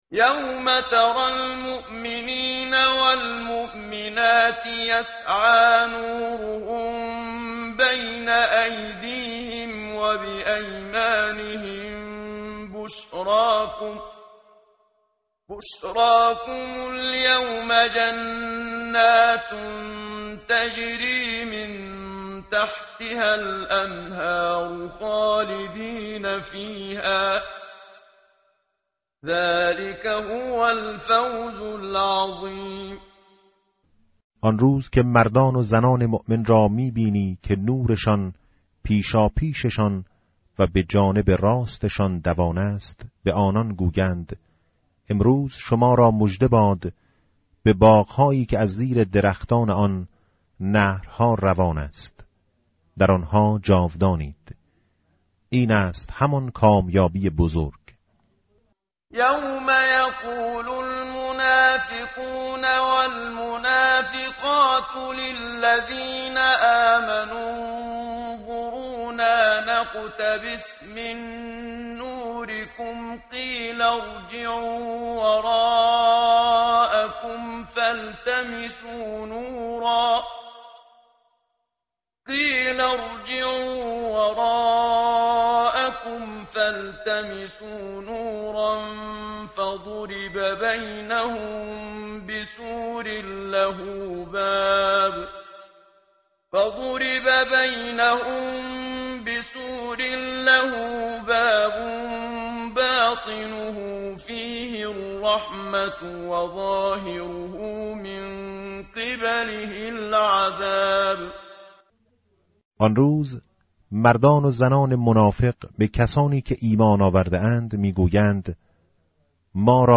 متن قرآن همراه باتلاوت قرآن و ترجمه
tartil_menshavi va tarjome_Page_539.mp3